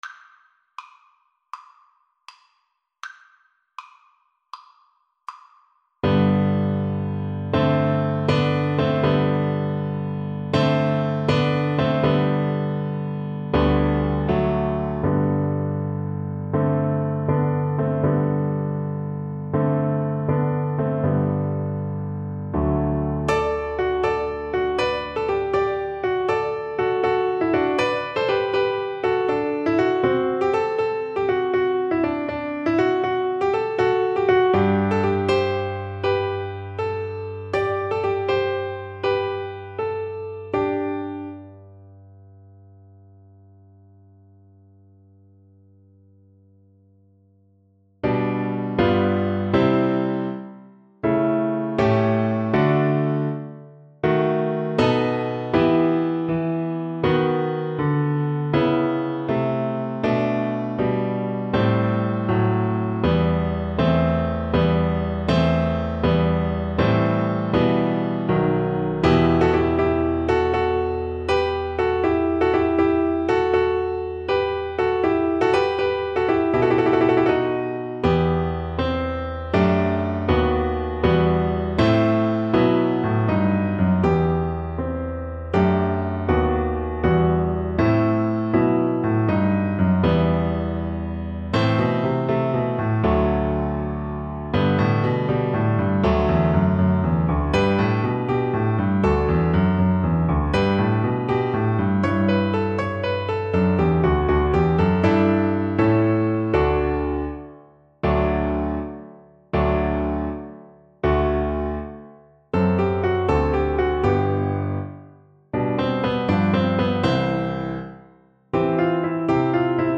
Free Sheet music for Soprano (Descant) Recorder
Play (or use space bar on your keyboard) Pause Music Playalong - Piano Accompaniment Playalong Band Accompaniment not yet available transpose reset tempo print settings full screen
G major (Sounding Pitch) (View more G major Music for Recorder )
Allegro (View more music marked Allegro)
12/8 (View more 12/8 Music)
Classical (View more Classical Recorder Music)